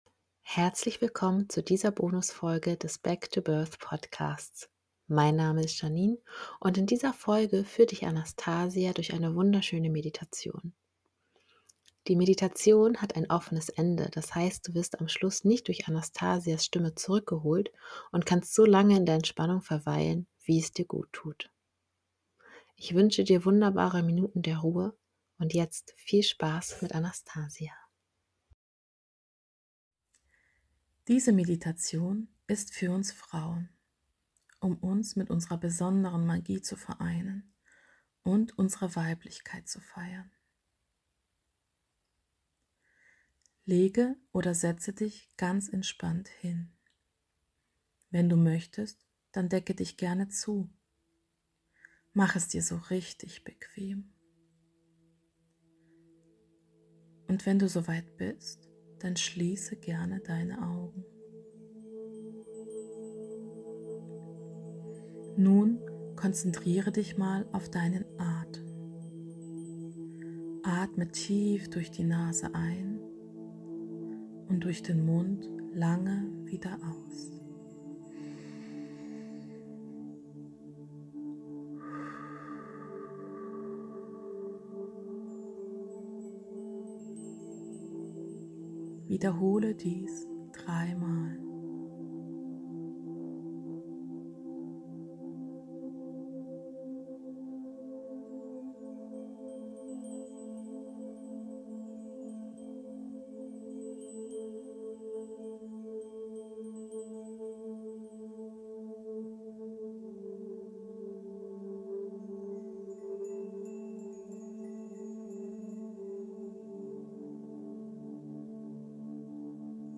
Licht-Meditation